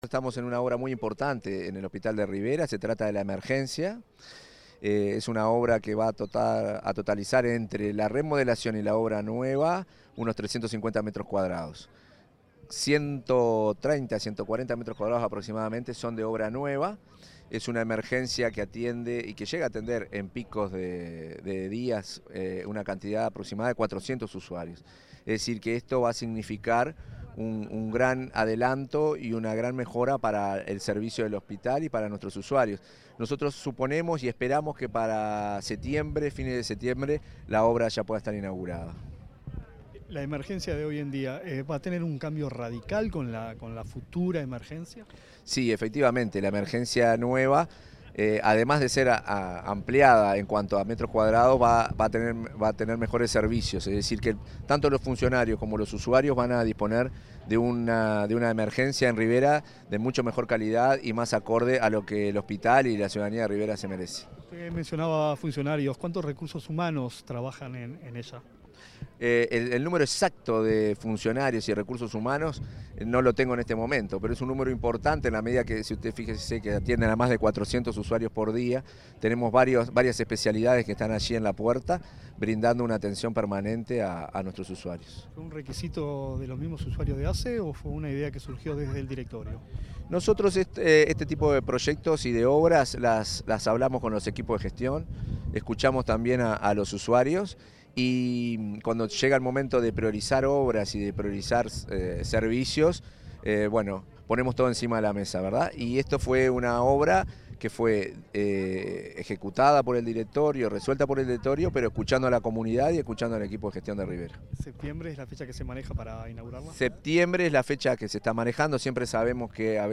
Entrevista al presidente de ASSE, Marcelo Sosa